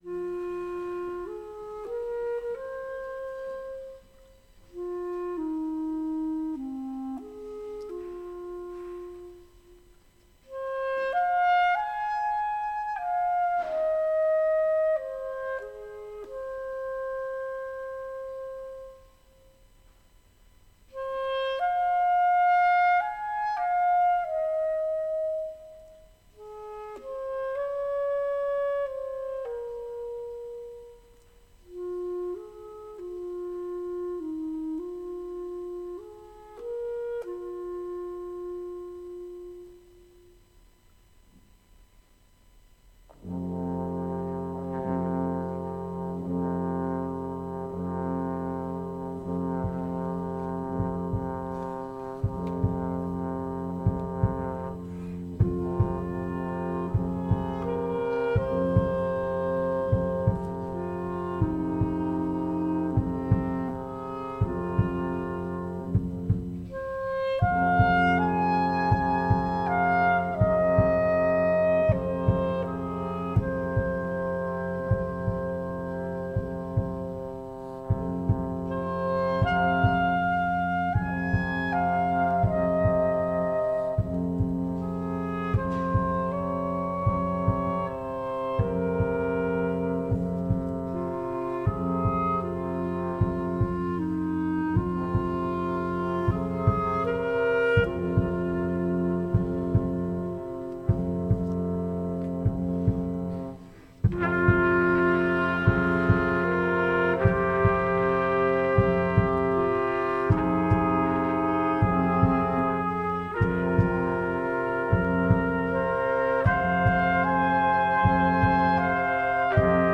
Tabernacle Folk Festival